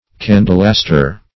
Search Result for " candlewaster" : The Collaborative International Dictionary of English v.0.48: Candlewaster \Can"dle*wast`er\, n. One who consumes candles by being up late for study or dissipation.